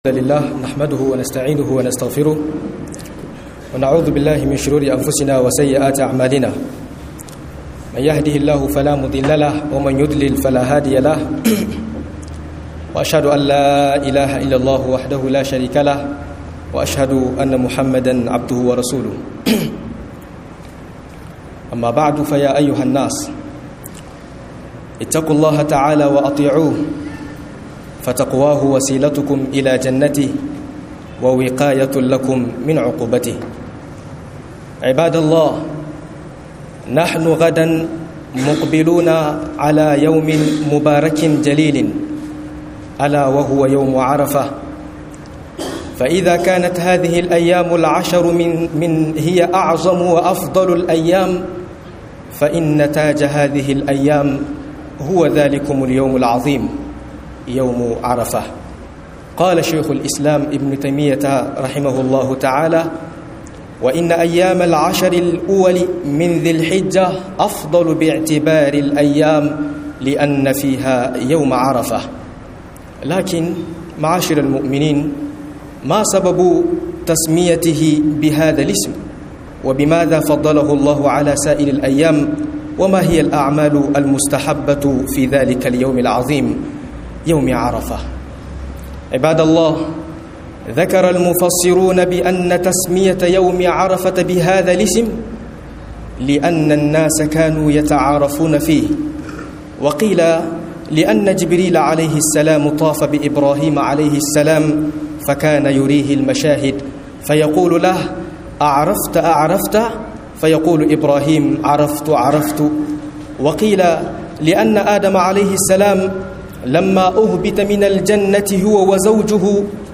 Falalar Ranar Arfa - MUHADARA